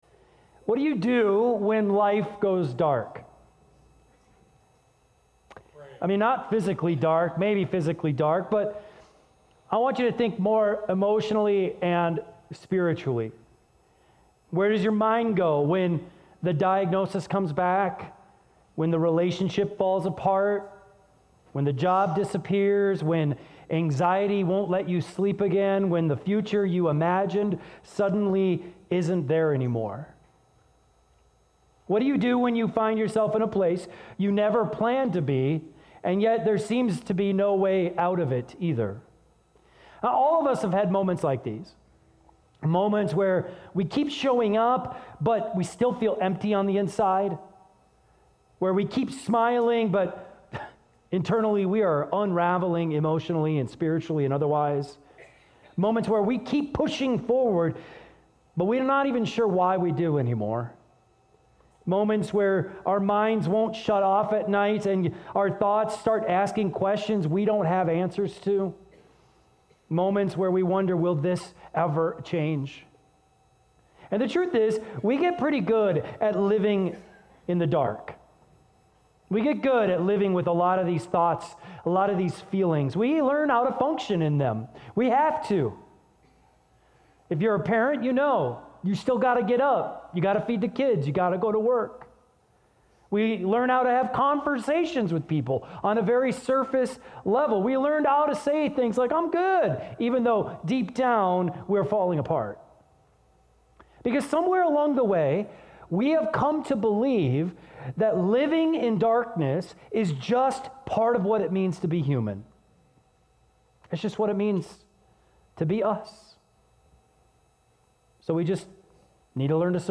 keyboard_arrow_left Sermons / Easter 2026 Series Download MP3